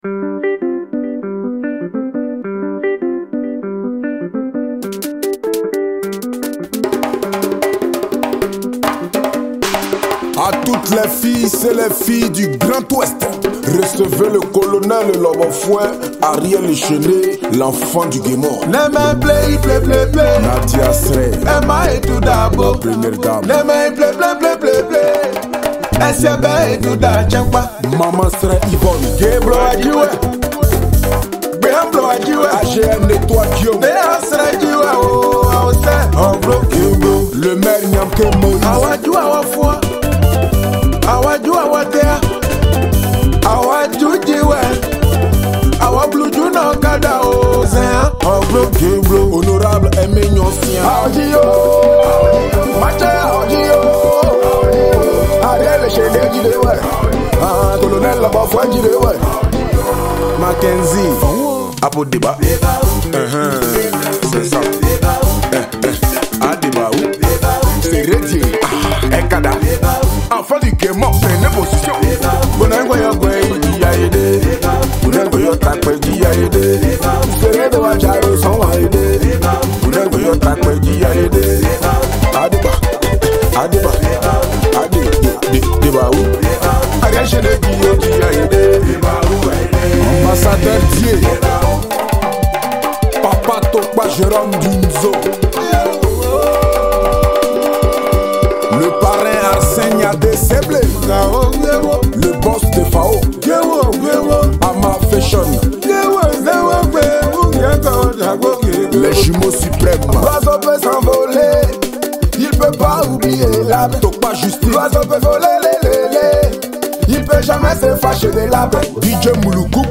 | Tradi-Moderne